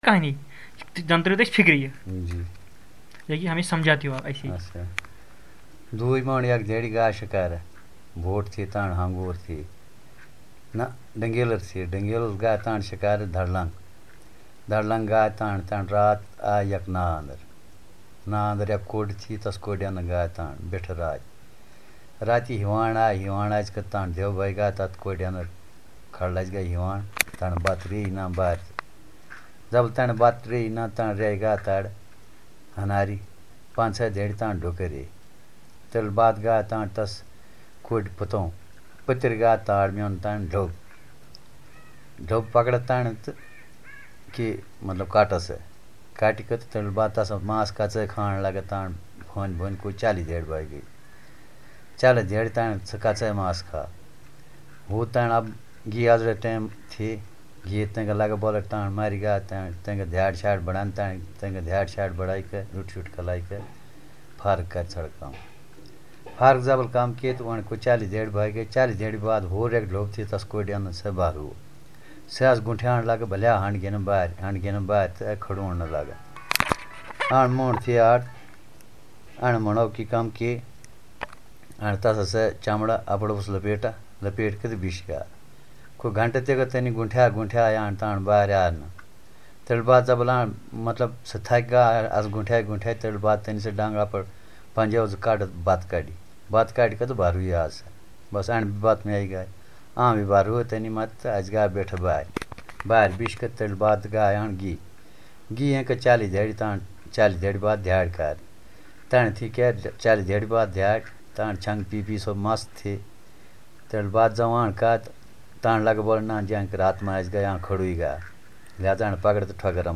Retelling of folktale
dc.description.elicitationmethodInterview method